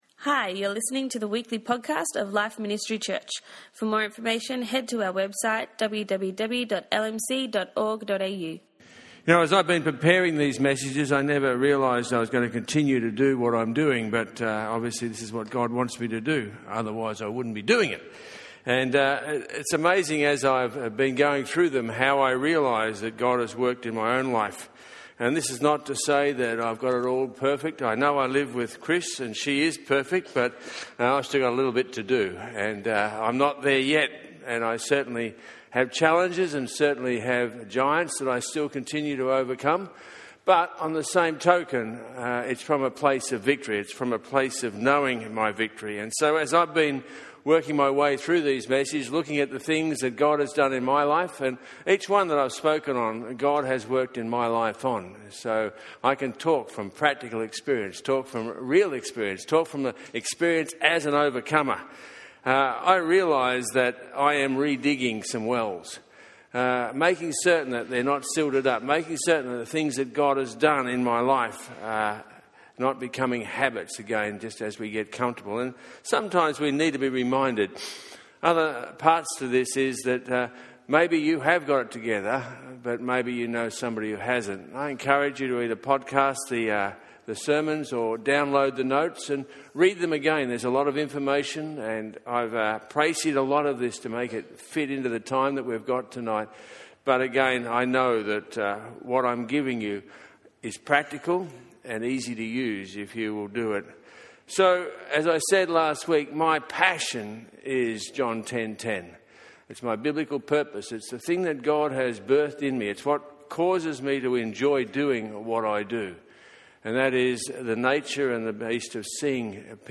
In this message on overcoming loneliness, he gave us practical application through the Word of God to prevent the enemy stealing what God has placed in us!